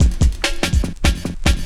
16 LOOP08 -R.wav